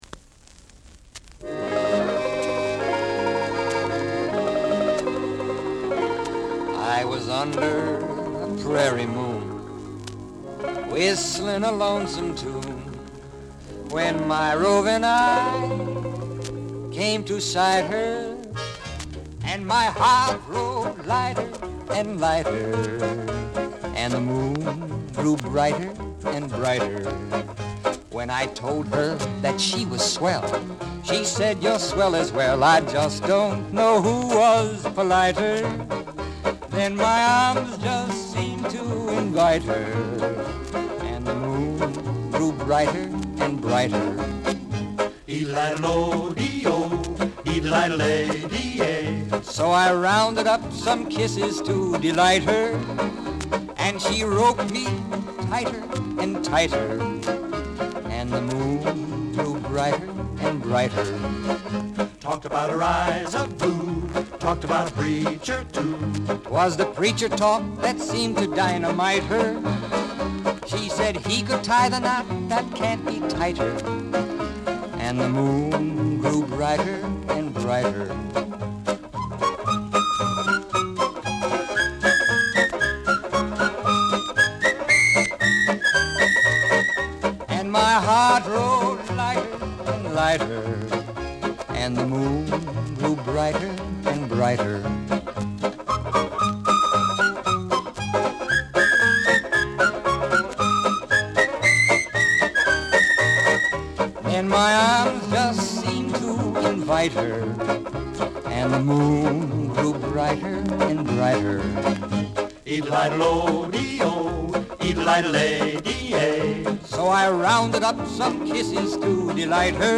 45 RPM Vinyl record